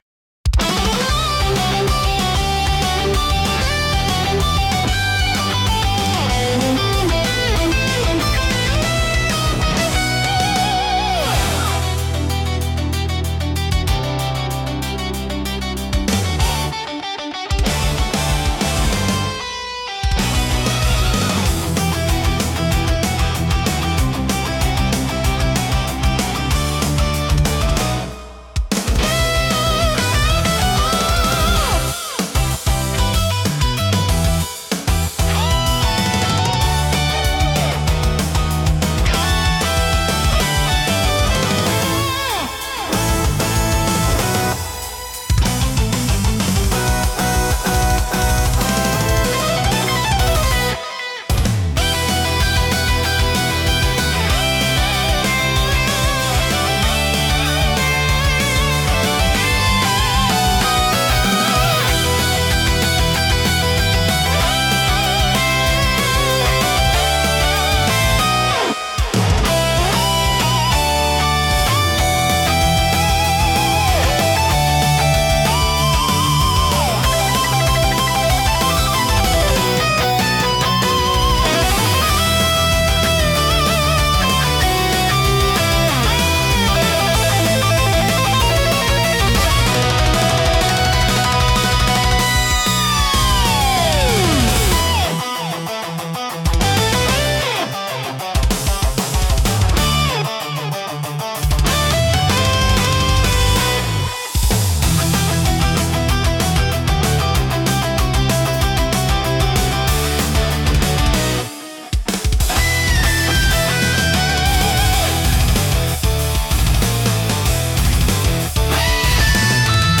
BGMとしては、ゲームや映像の戦闘シーンに最適で、激しいアクションと感情の高まりを盛り上げます。
エネルギッシュかつ感動的なシーン演出に非常に向いているジャンルです。